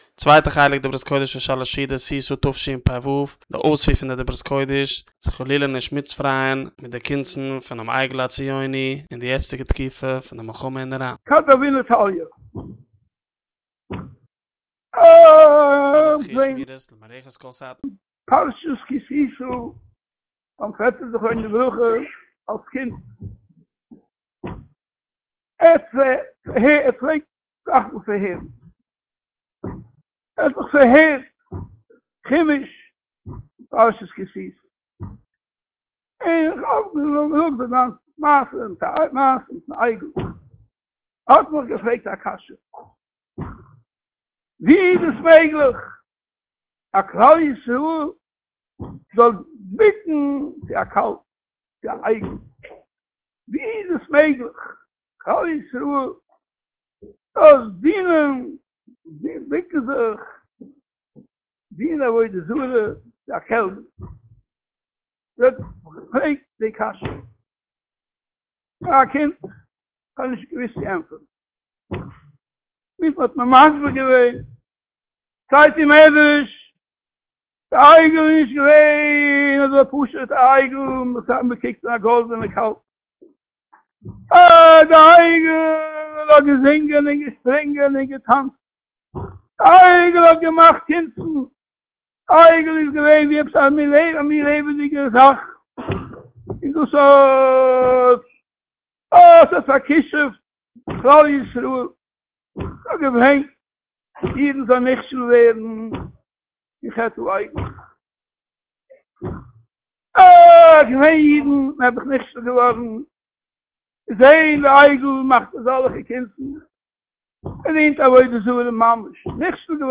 דברות קודש